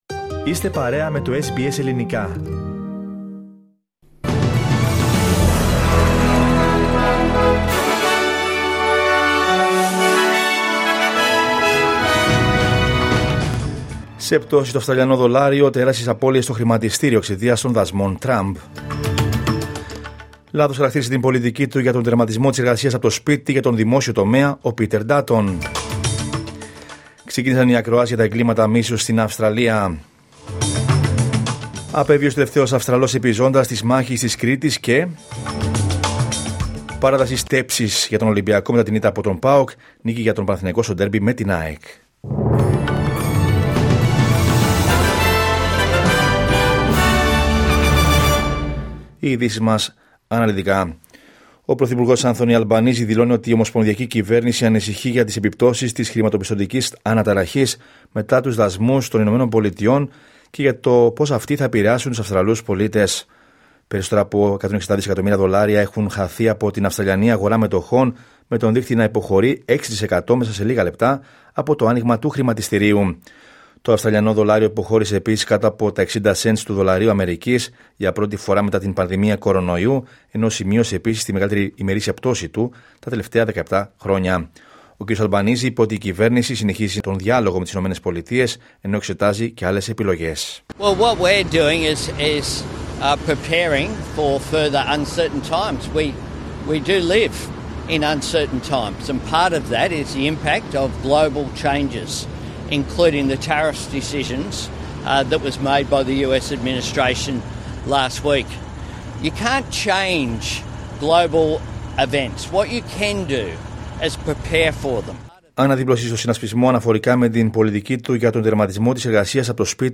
Δελτίο Ειδήσεων Δευτέρα 7 Απριλίου 2025